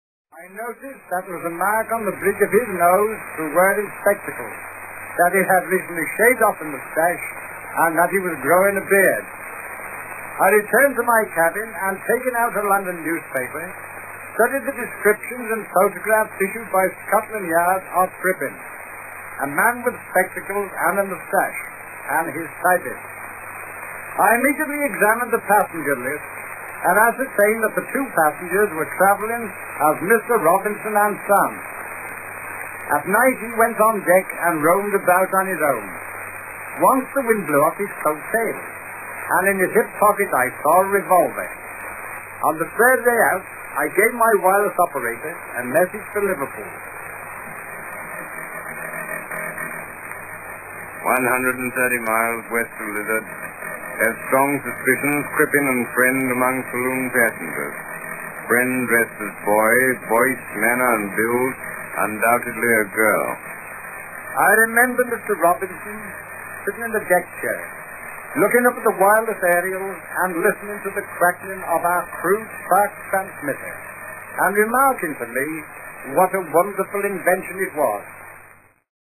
Voici une entrevue inédite